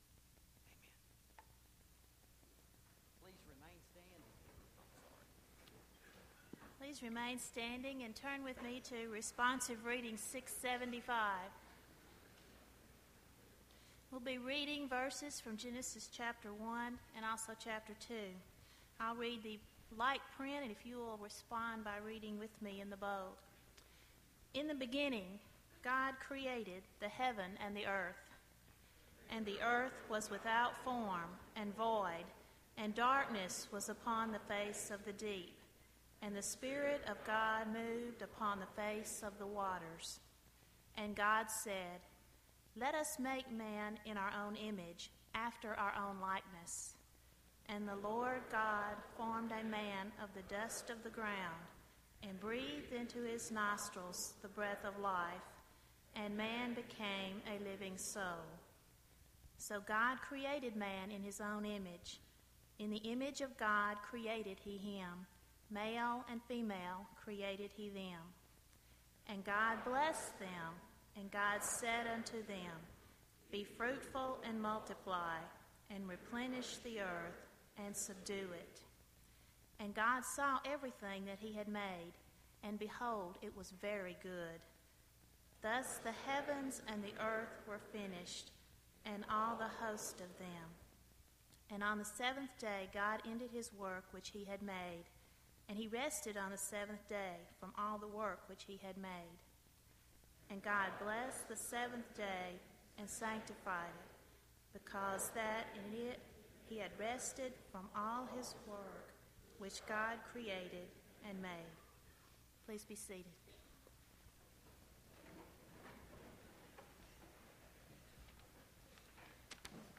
Founders' Day Chapel